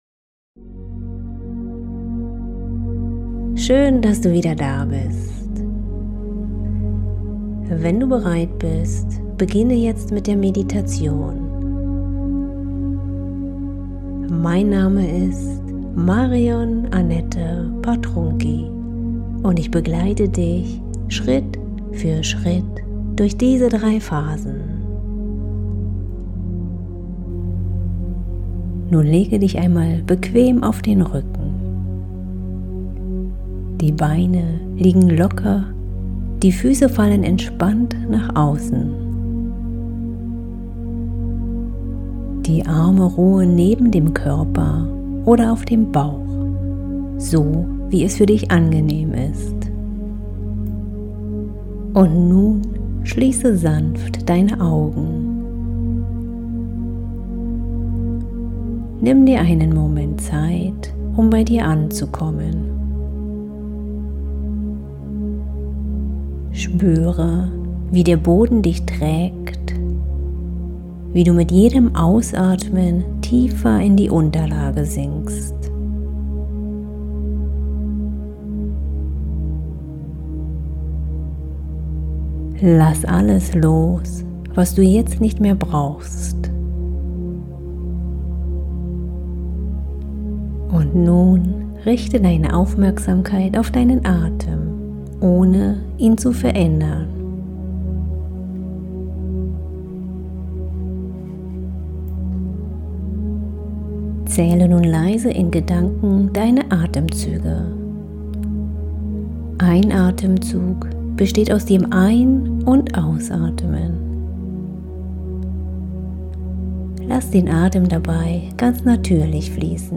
Tuna-Atmung – Meditation mit dieser Aufnahme kannst du direkt üben.